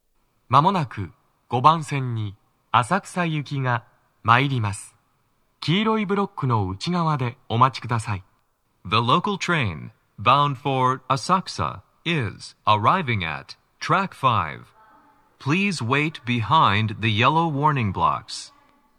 スピーカー種類 TOA天井型
鳴動は、やや遅めです。
5番線 上野・浅草方面 接近放送 【男声
gomotesando5sekkin.mp3